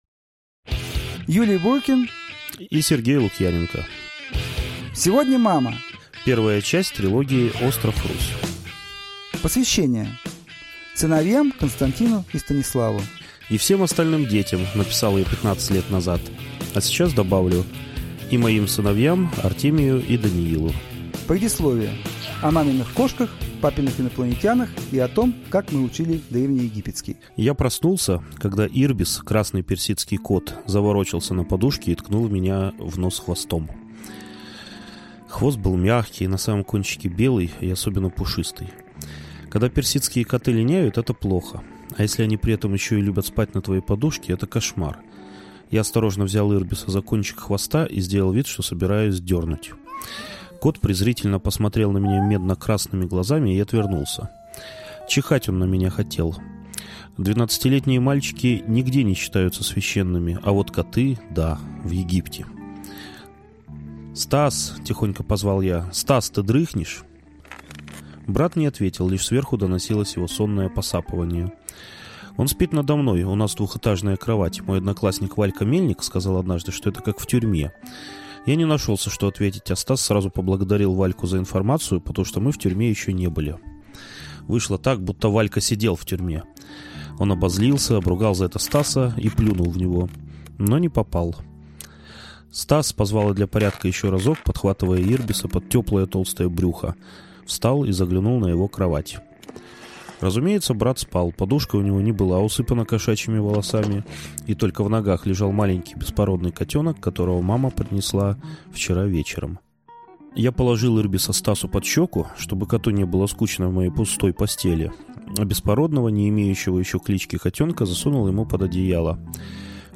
Аудиокнига Остров Русь | Библиотека аудиокниг